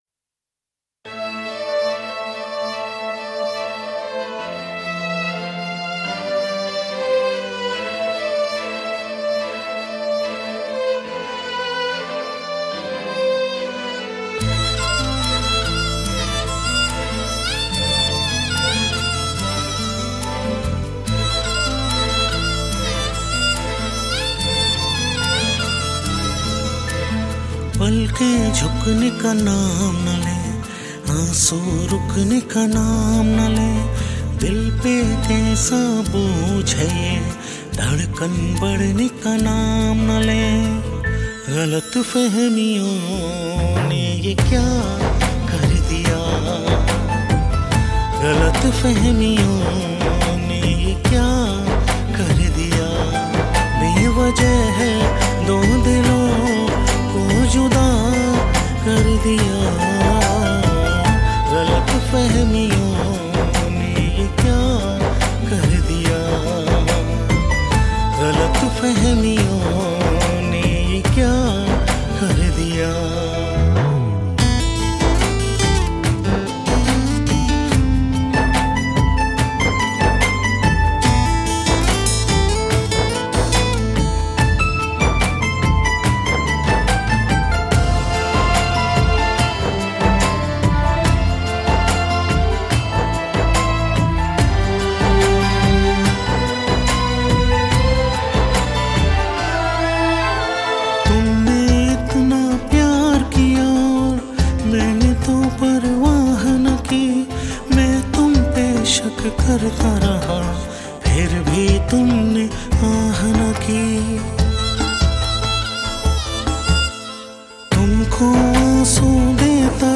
Bollywood Mp3 Music